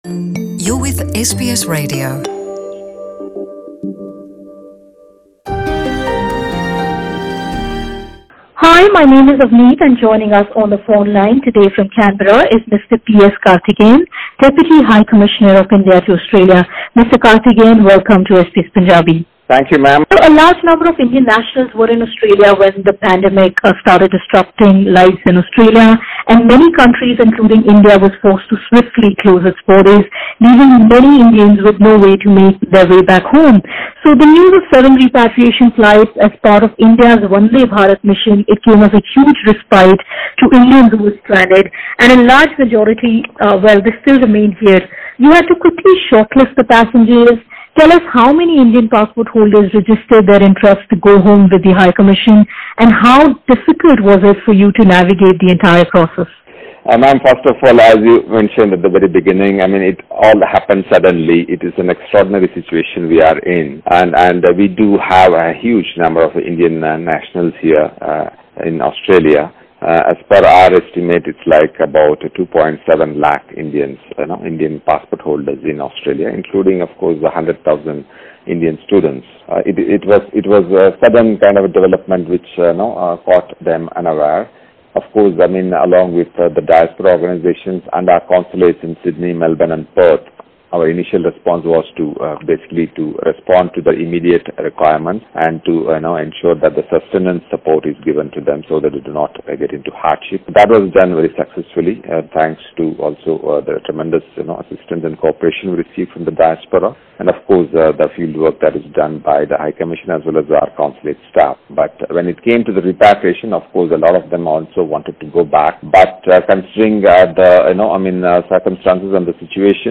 Nearly 10,000 Indian passport holders stranded in Australia who wish to return home registered their interest with the High Commission, said the Deputy High Commissioner of India, P.S. Karthigeyan in an exclusive interview with SBS Punjabi.